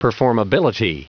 Prononciation du mot performability en anglais (fichier audio)